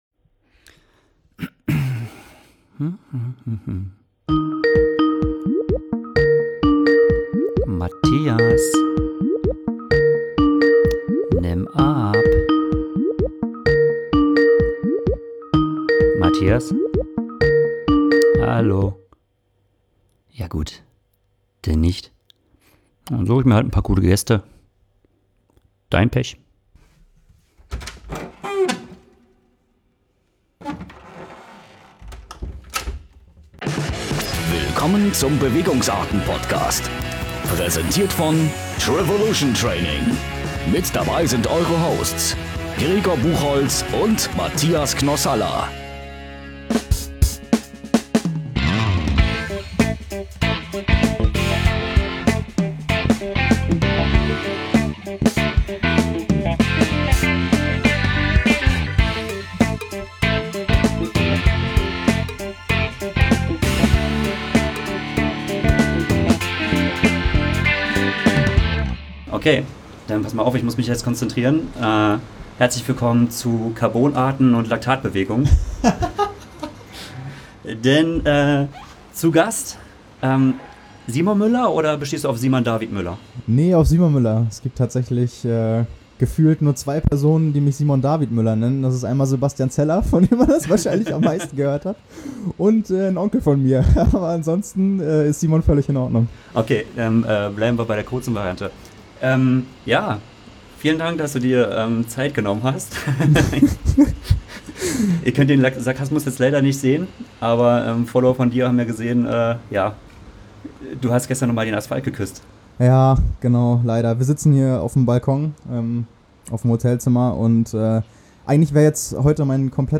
Das heißt Mikro in die Trikottasche und immer Aufnahmebereit sein.